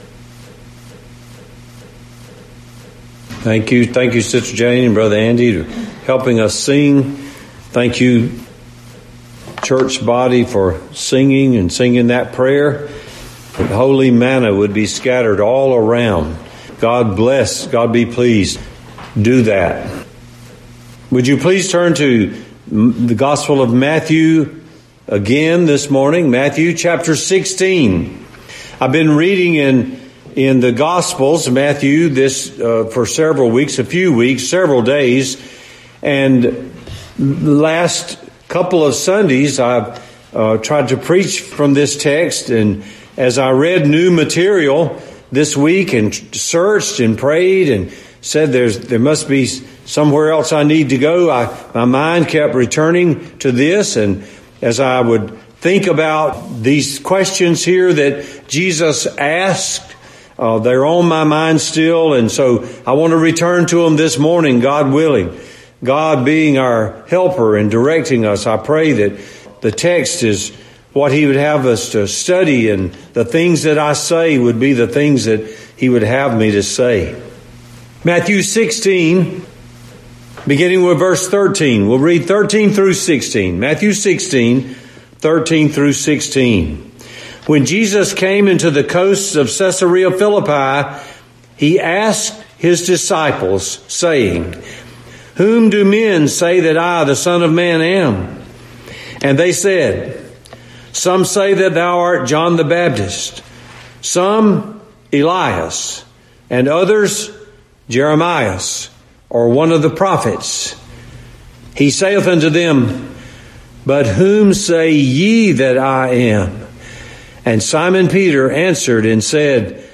Matthew 16:13-16, Whom Say Ye That I Am, #2 Feb 26 In: Sermon by Speaker